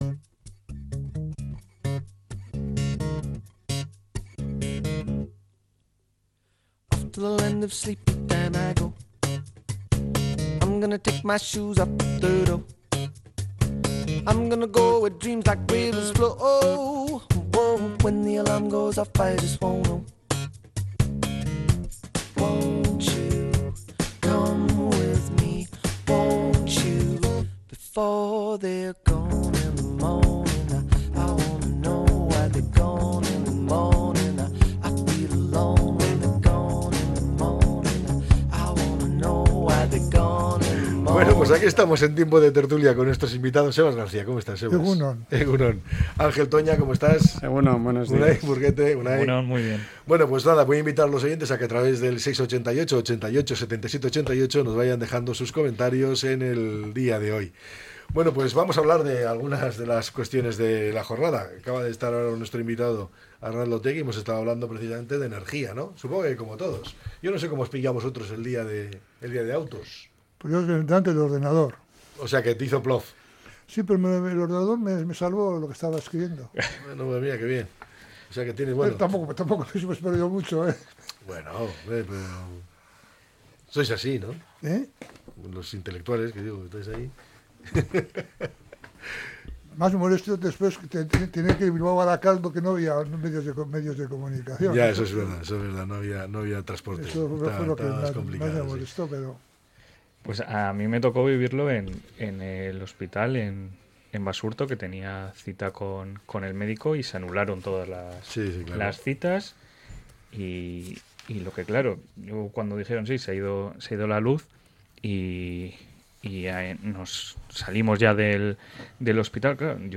La tertulia 30-04-25.